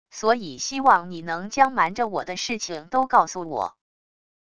所以希望你能将瞒着我的事情都告诉我wav音频生成系统WAV Audio Player